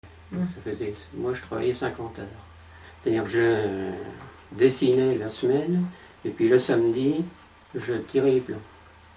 Figure 04 : Extrait vidéo du témoignage d’un dessinateur.